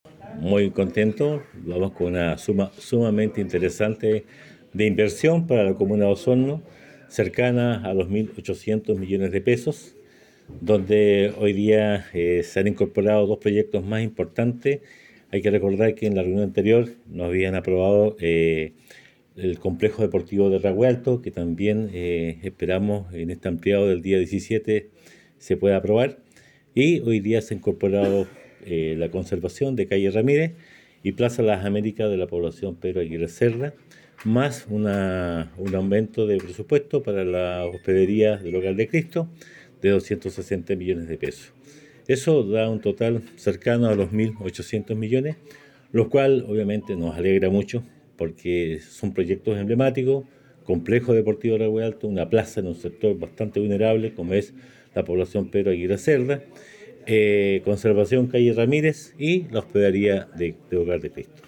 Estos proyectos deben ser ratificados por la mesa plenaria del Consejo Regional a realizarse el día jueves, ante lo que el Alcalde Emeterio Carrillo señaló que la inversión que supera los 1.800 millones para obras emblemáticas.